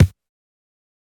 BattleCatEastSideKick.wav